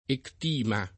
ectima [ ekt & ma ] s. m. (med.); pl. ‑mi